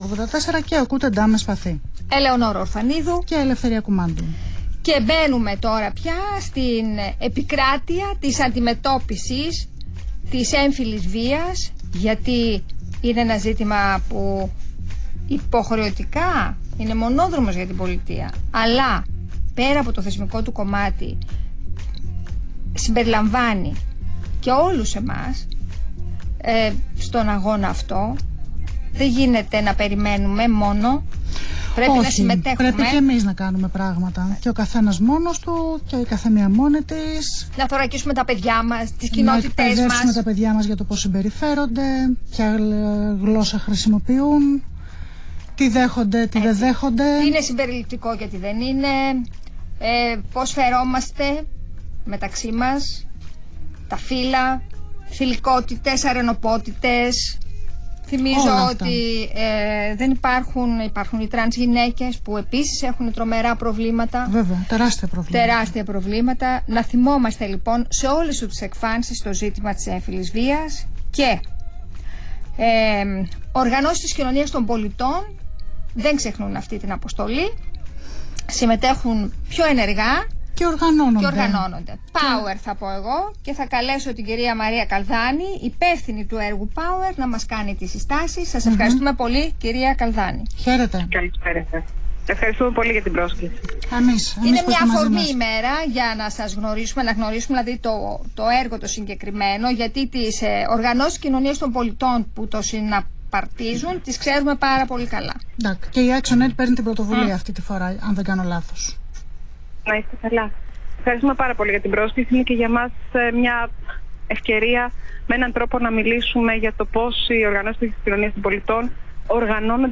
Συνέντευξη στο ραδιόφωνο του Αθήνα 9.84 για την Διεθνή Ημέρα για την εξάλειψη της βίας κατά των γυναικών
Συνέντευξη